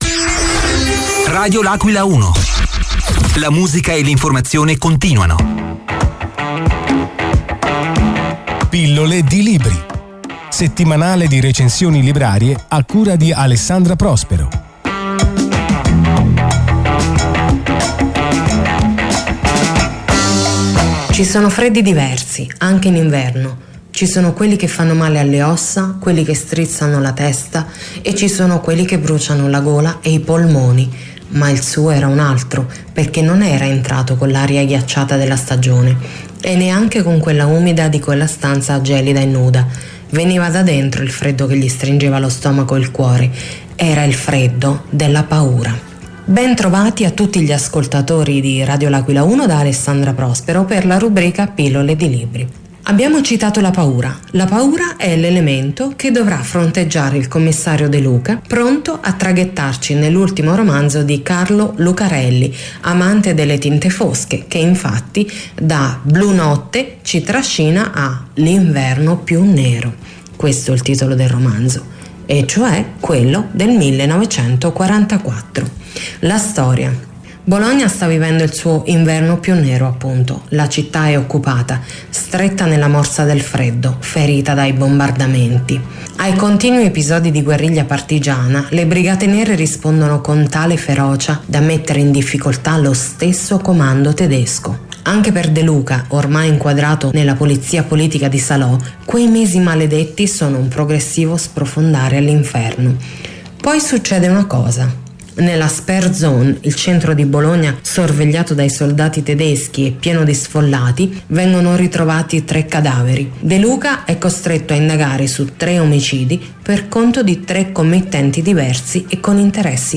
Il libro commentato in diretta per gli ascoltatori di Radio L’Aquila 1 è infatti “L’inverno più nero”, edito da Einaudi e uscito ai primi di marzo 2020. A fare da sfondo all’ultima indagine del commissario De Luca una Bologna cupa e sofferente, ferita dai bombardamenti: l’inverno più nero è infatti quello del 1944, minuziosamente rievocato e raccontato da Lucarelli.